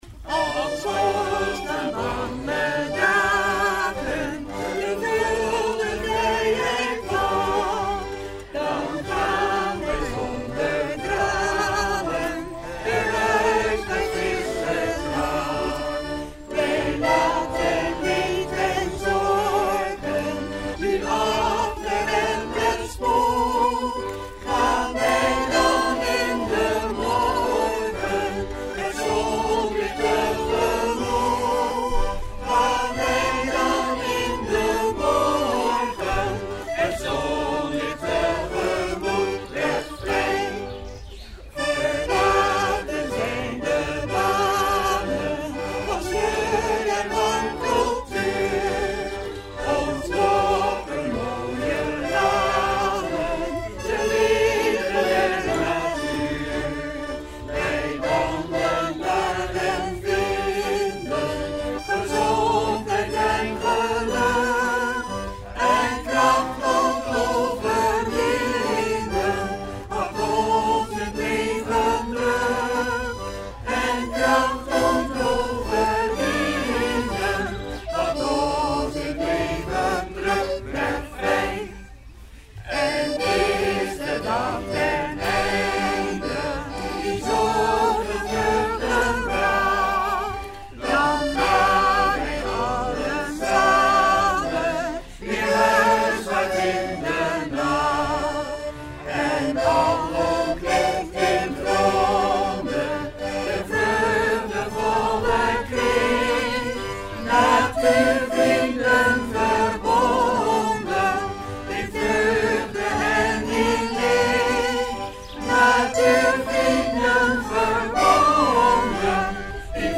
Zang- en muziekgilde, Pinksteren 2006